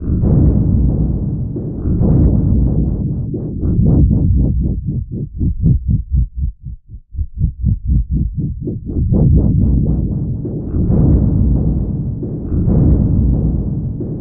FX 135-BPM 1.wav